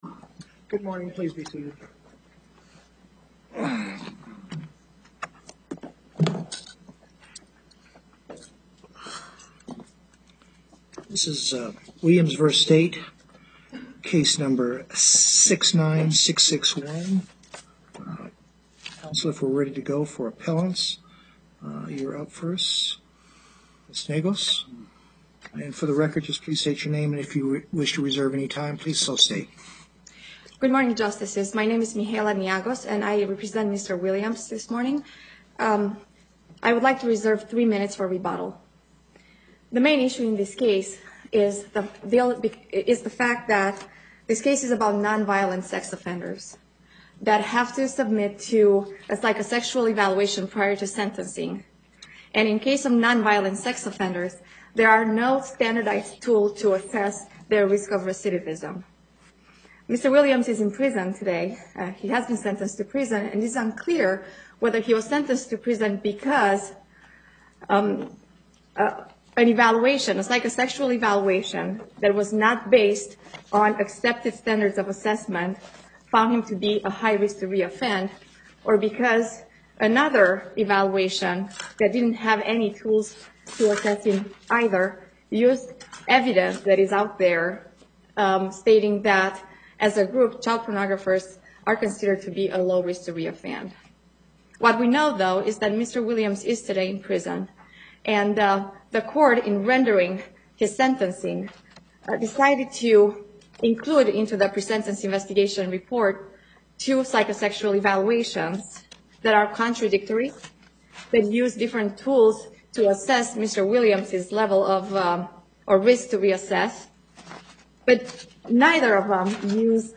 Location: Carson City Before the Southern Panel, Justice Douglas Presiding